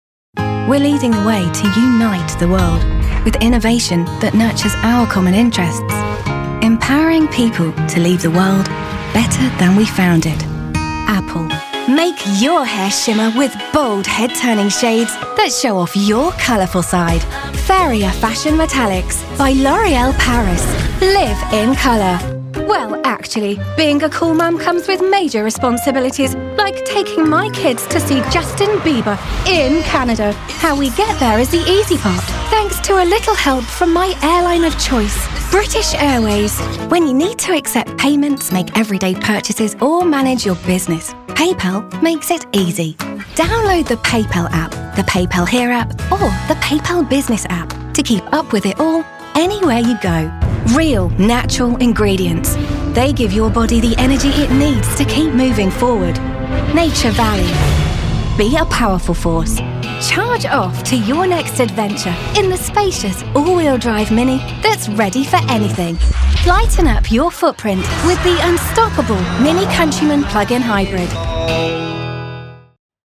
She is softly spoken and easy on the ears.
british english
commercial
authoritative
friendly